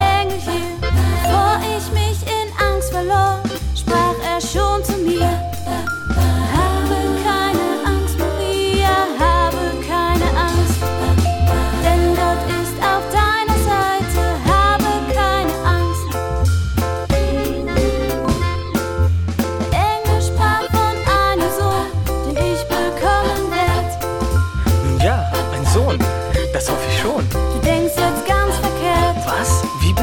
• Sachgebiet: Advent/Weihnachten Musik (Christmas)